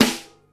• Classic Treble-Heavy Rap Steel Snare Drum Sample A# Key 203.wav
Royality free snare sound tuned to the A# note. Loudest frequency: 2197Hz
classic-treble-heavy-rap-steel-snare-drum-sample-a-sharp-key-203-AAX.wav